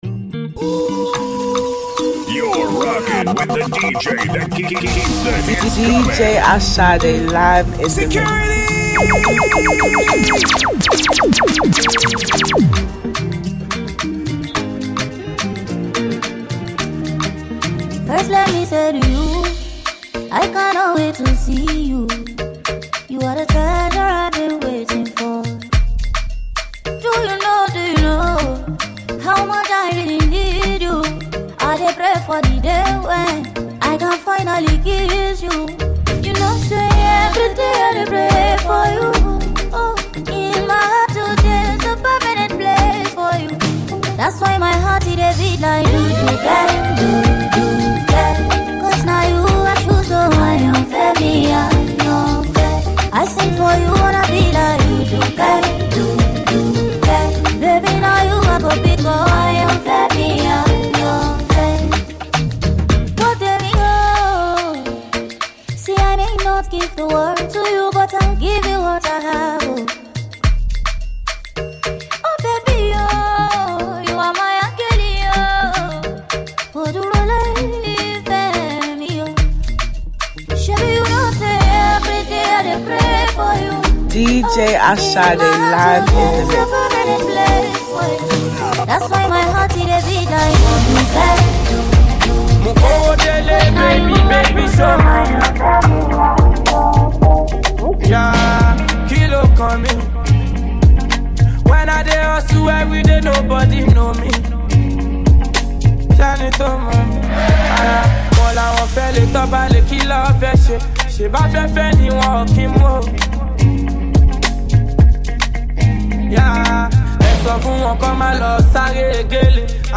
This mixtape is the compilation of trending songs in Africa.